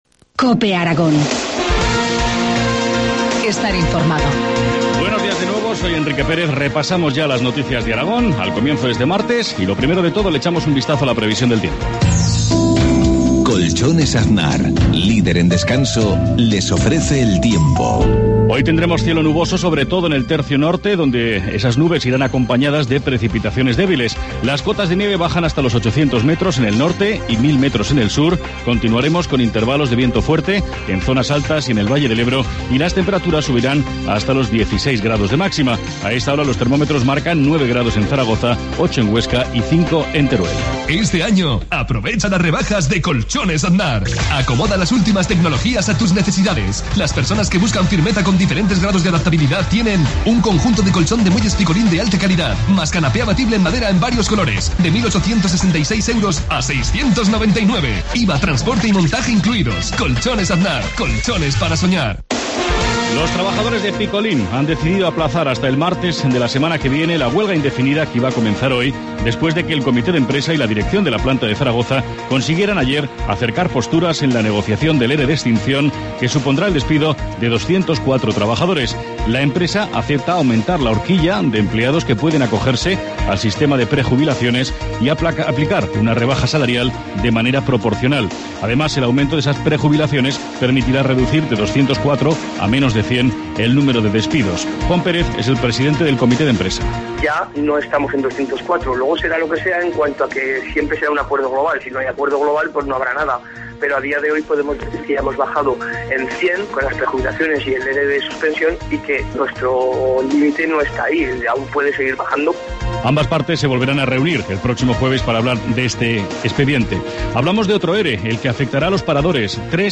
Informativo matinal, martes 5 de febrero, 7.53 horas